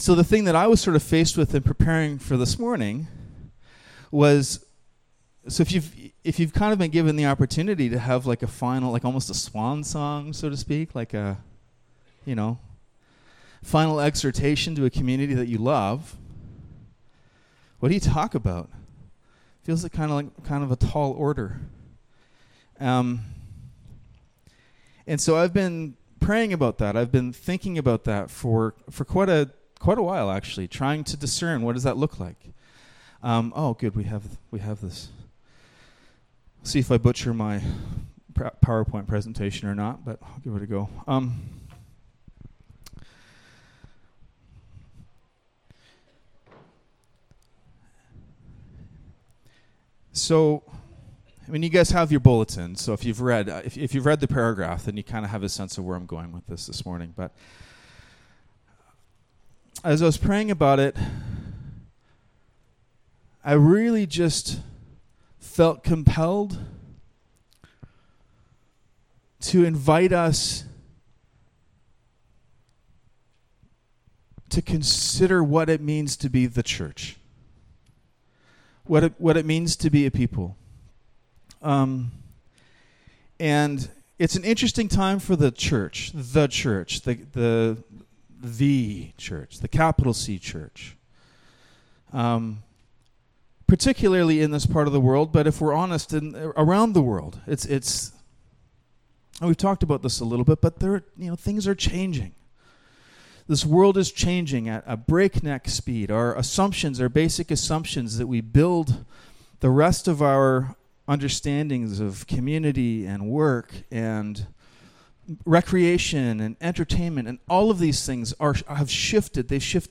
1 John 4:19-20 Service Type: Sunday Morning Bible Text